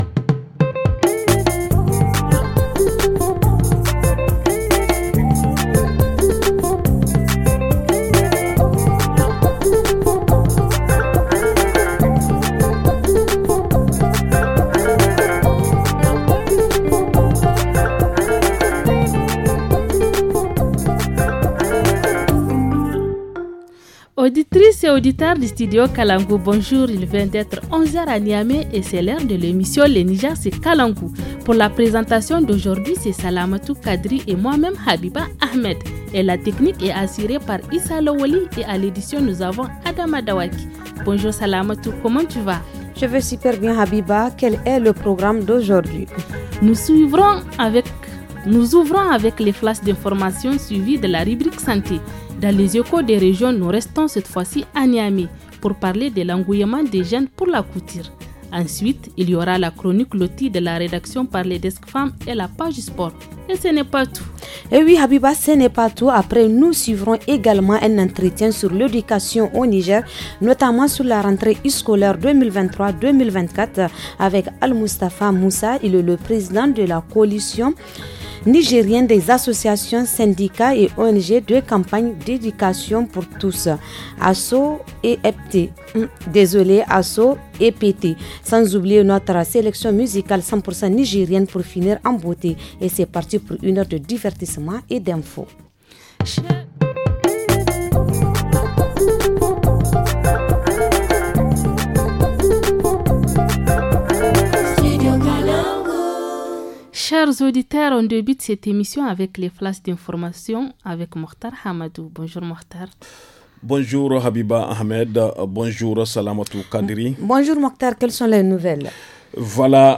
Entretien
Reportage région